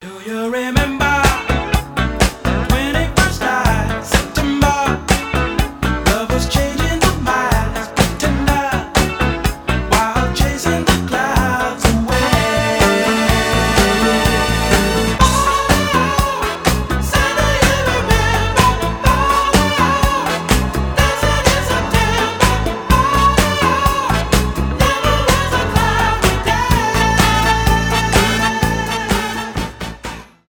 танцевальные
фанк
диско